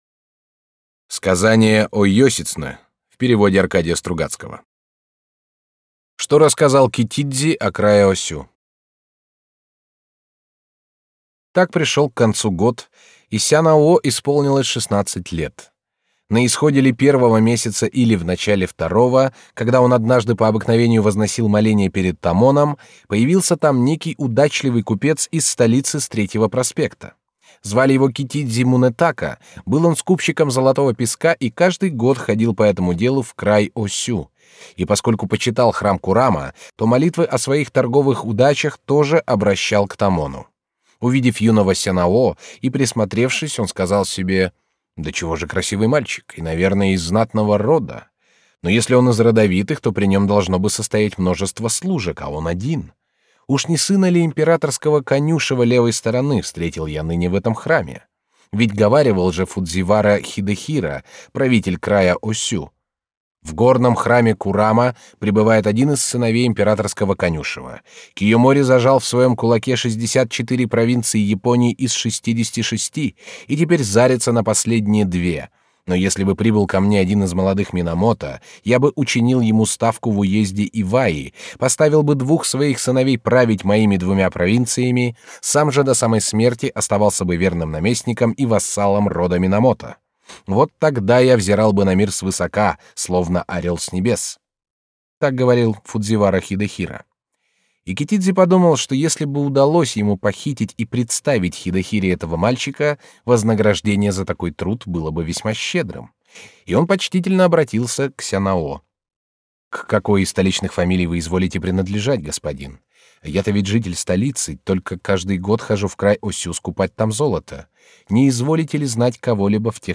Аудиокнига Сказание о Ёсицунэ | Библиотека аудиокниг